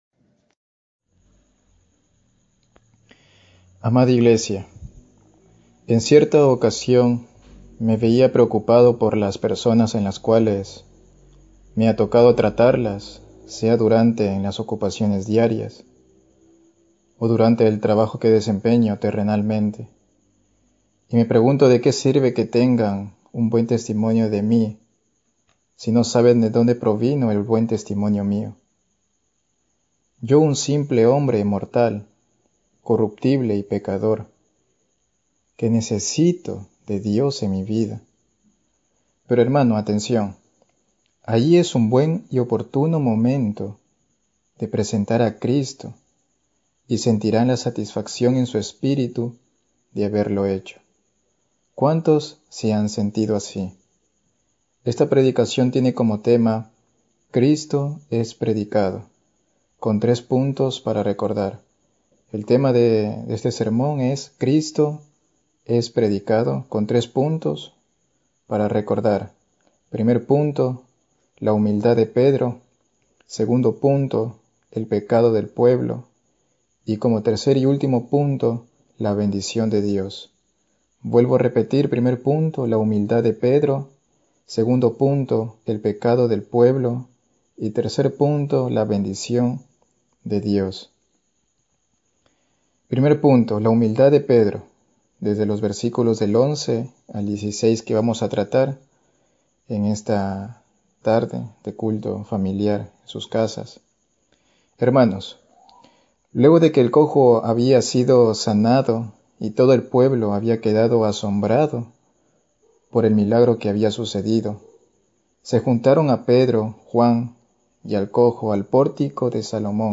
Hechos 3:11-26 Tipo: Sermón Bible Text